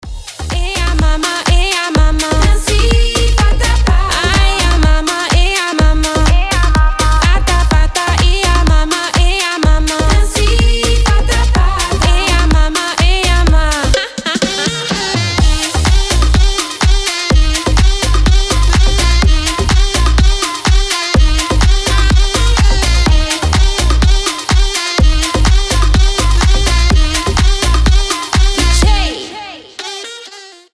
танцевальные , зажигательные , африканские